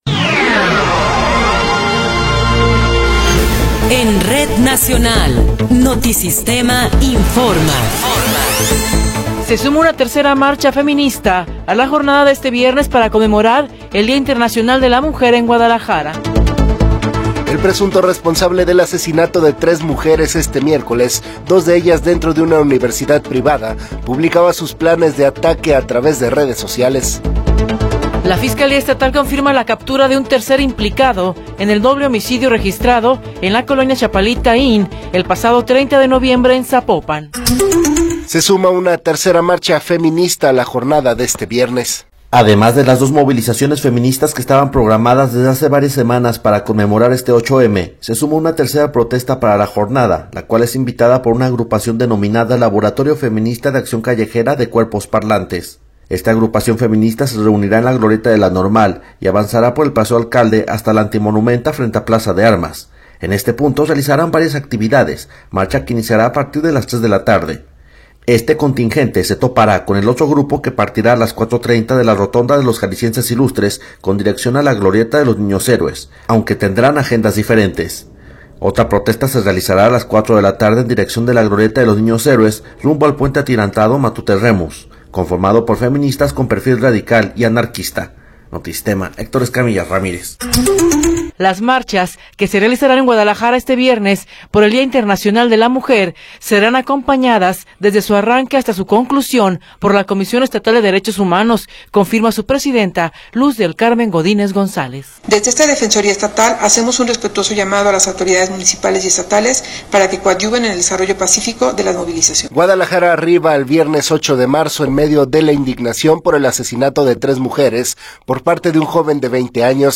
Noticiero 9 hrs. – 8 de Marzo de 2024
Resumen informativo Notisistema, la mejor y más completa información cada hora en la hora.